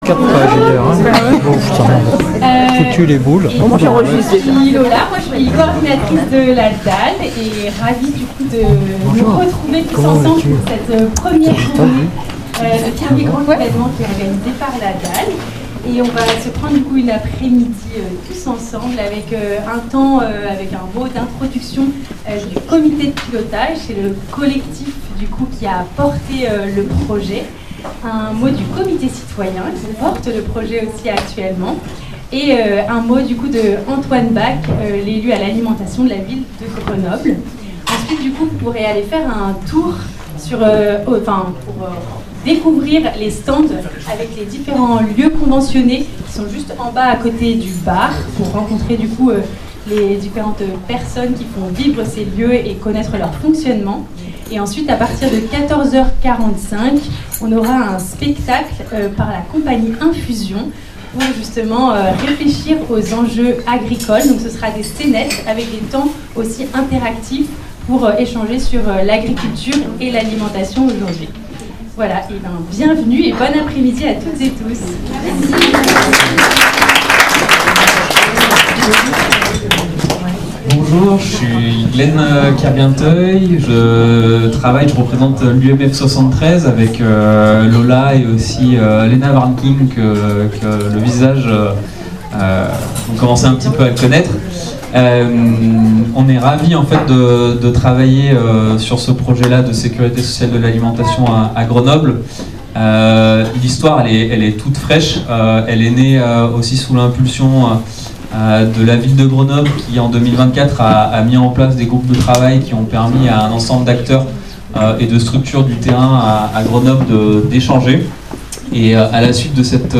Jusqu’ici, les habitant(e)s membres du comité citoyen ne s’étaient pas exprimé(e)s. Écoutons les messages, très rédigés, que ces personnes ont voulu faire passer ! Enregistrement des discours : le fichier mp3 (une vingtaine de minutes au total, y compris le discours de l’élu de la Ville de Grenoble).